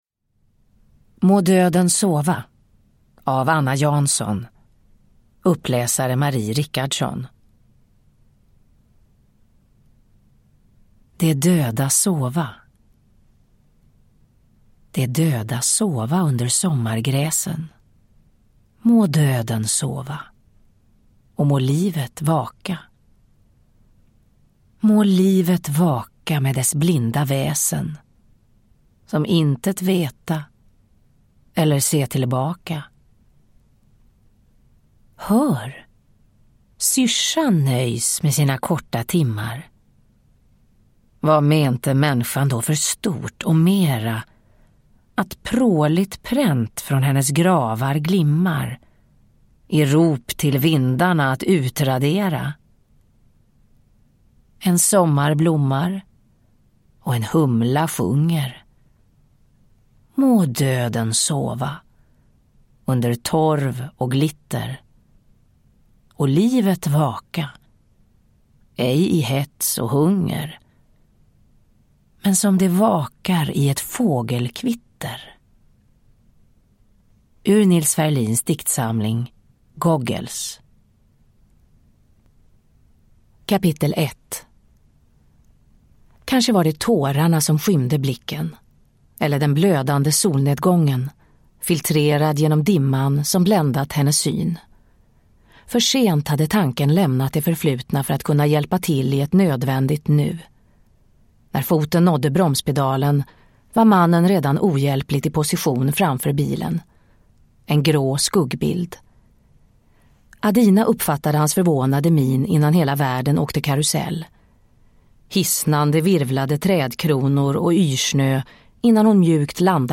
Må döden sova (ljudbok) av Anna Jansson